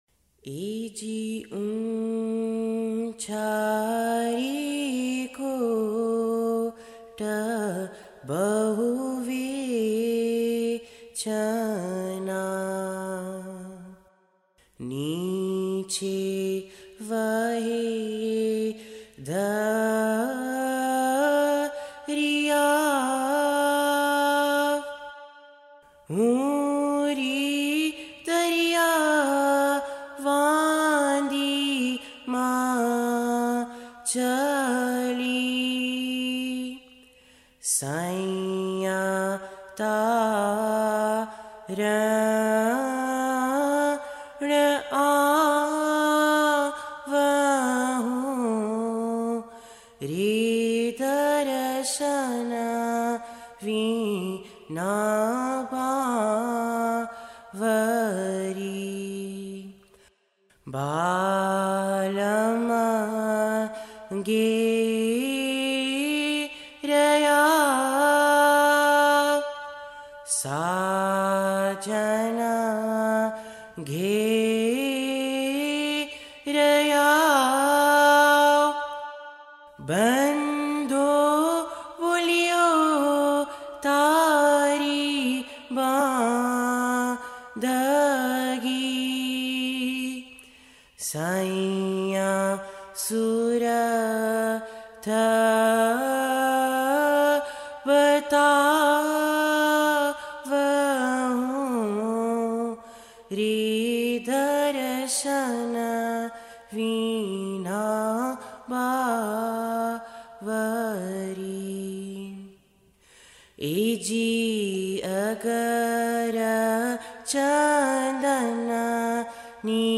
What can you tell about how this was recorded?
We are presenting four different renditions from various parts of the world. Portugal